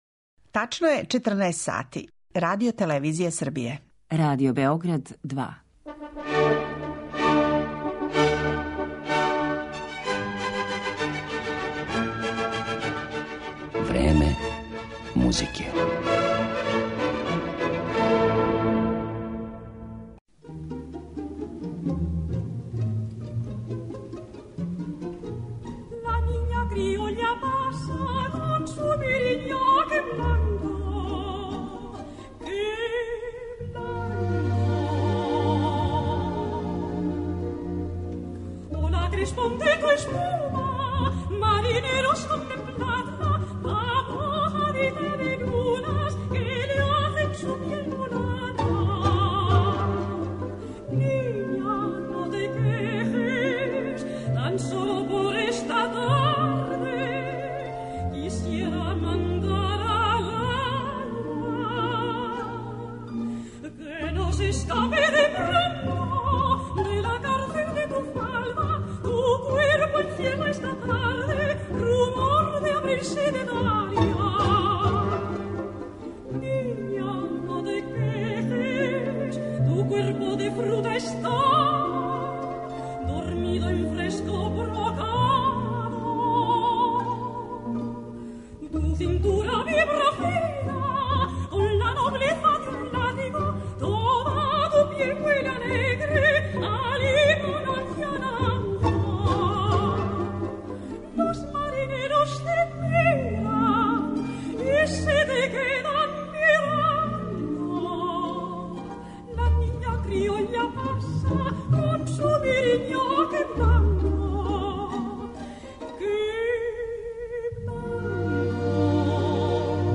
хабанера